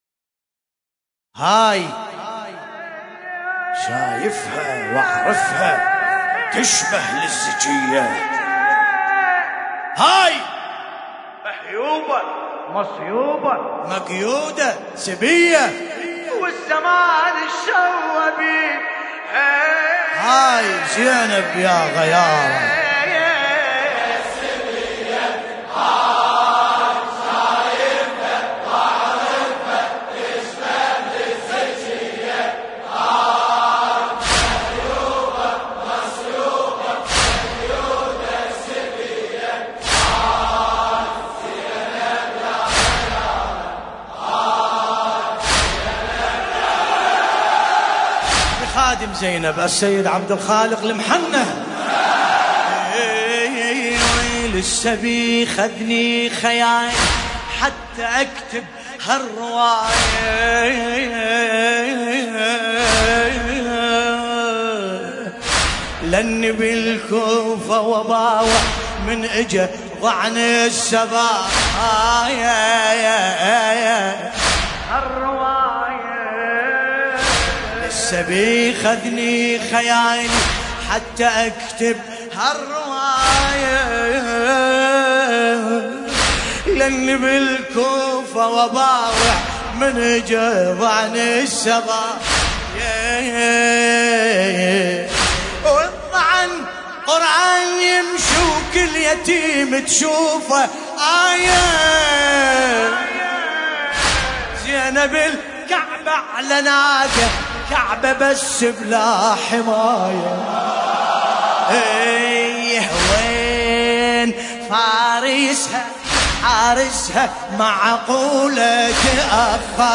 المناسبة : ليلة 17 محرم 1440 هـ